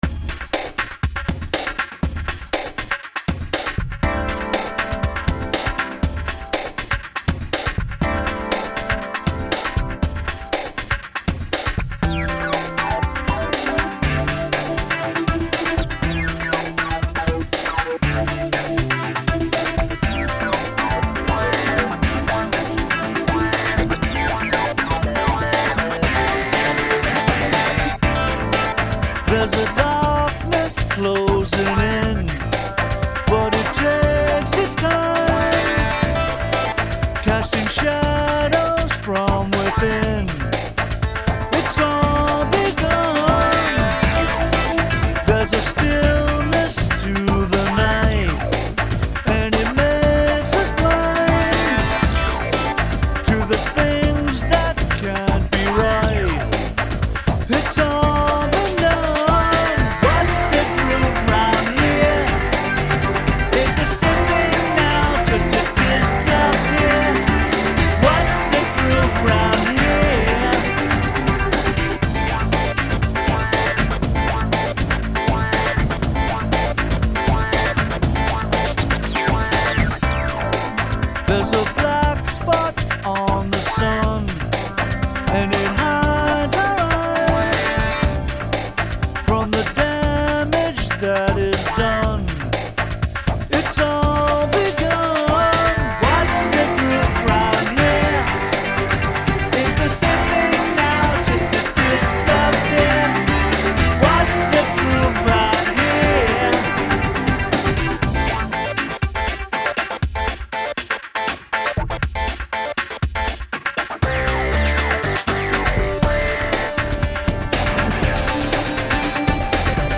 (Dance) Arranged, Mixed NO plug-ins 4:47.zip